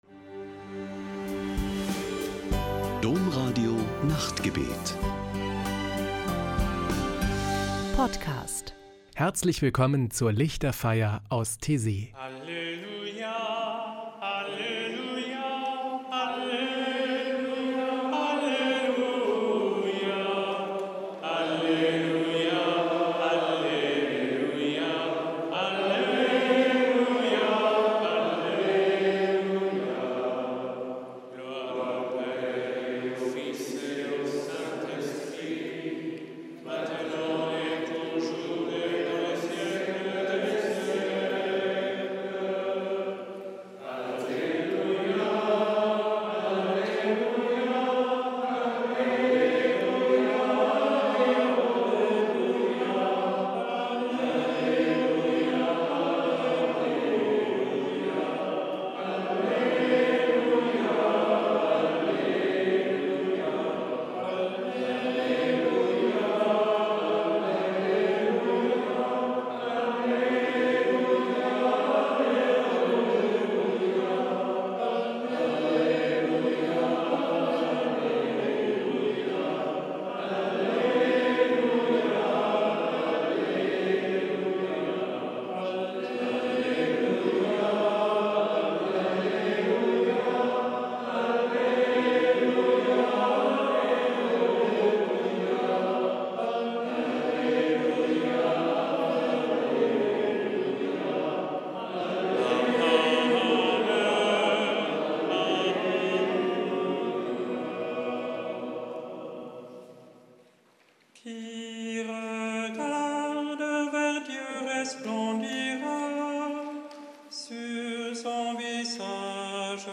Ein Höhepunkt jede Woche ist am Samstagabend die Lichterfeier mit meditativen Gesängen und Gebeten.